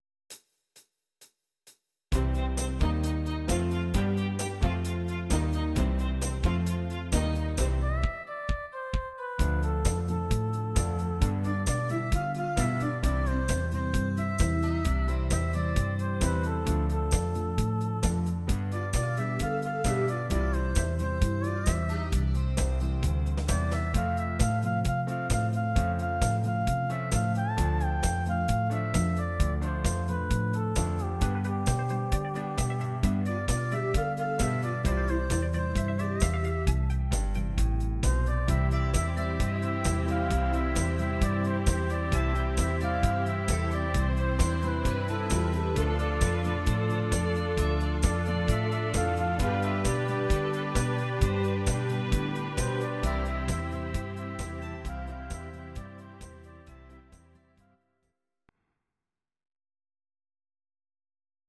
Audio Recordings based on Midi-files
Country, 1970s